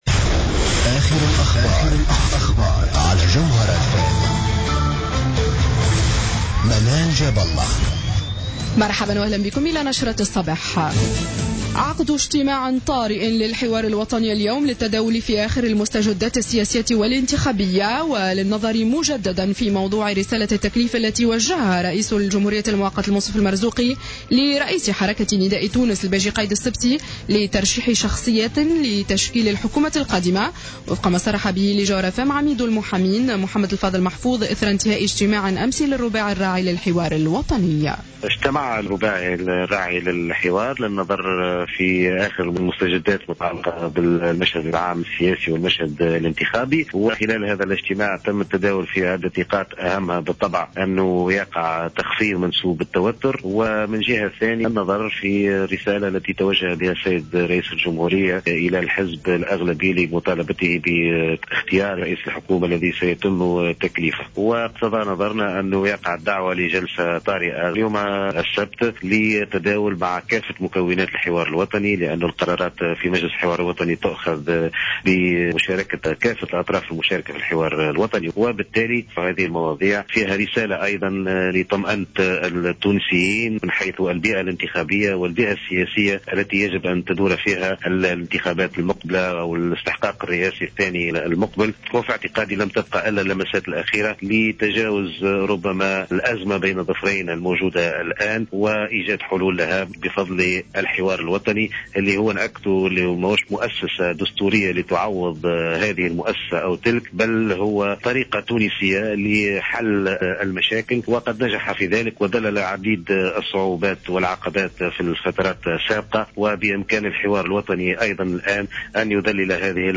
نشرة أخبار السابعة صباحا ليوم السبت 29-11-14